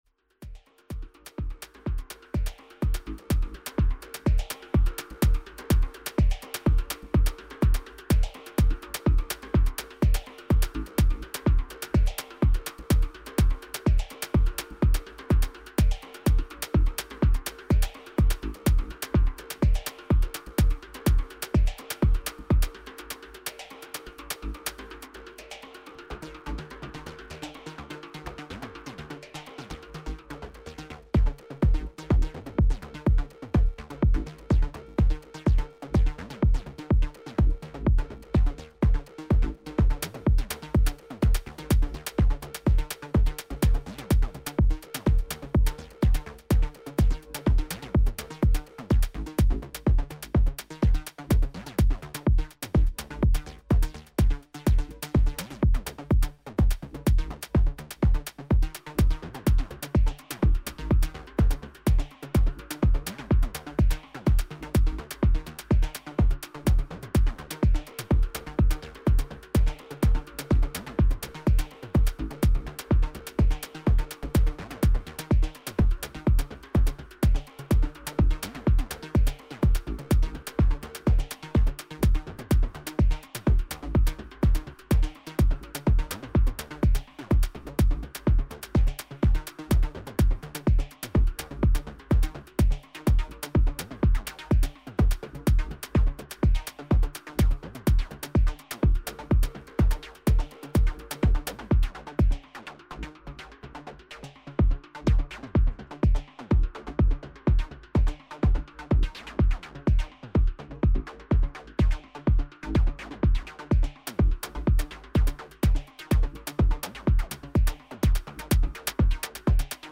Bassline and synth from a Moog Slim Phatty. Arranged on with drum loop on the OT. Playing with merging 4:4 and 3:4…